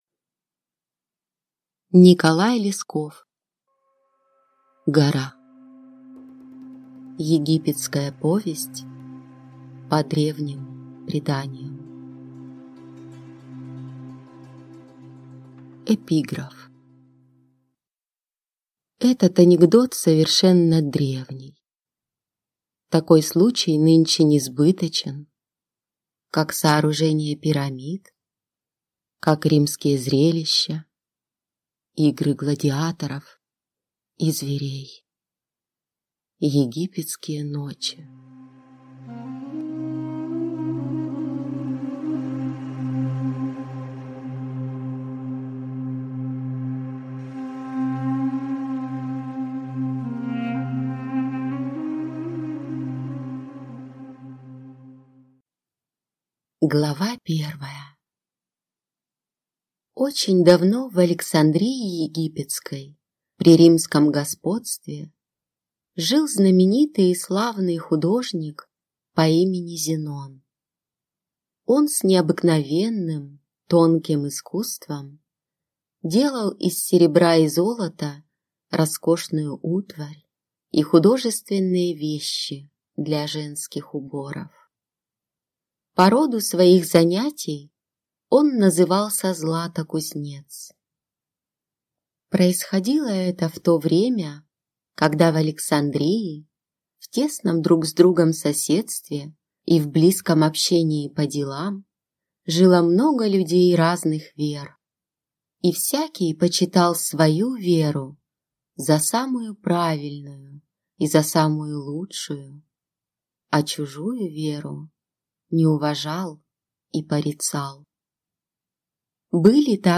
Аудиокнига Гора | Библиотека аудиокниг